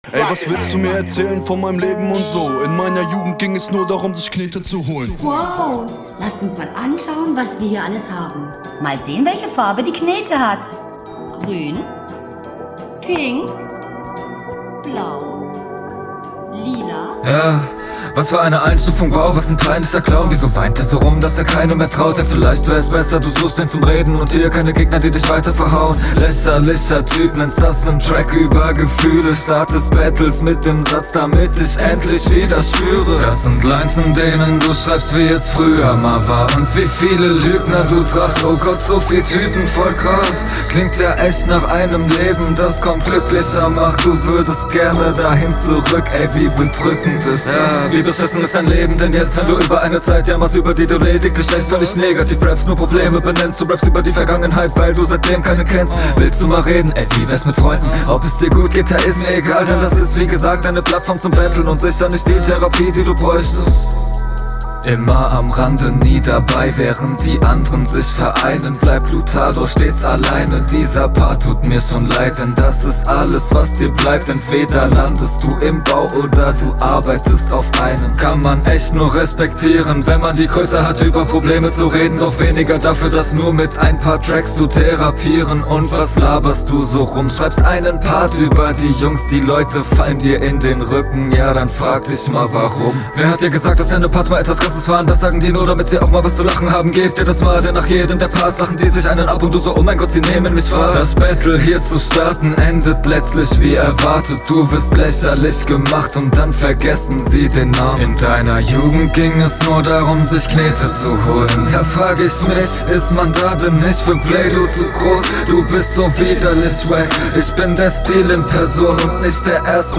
Intro des Gegners Reinschneiden ist gut, nichts besonderes, aber nicht super Störend Flow: Ziemlich nice, …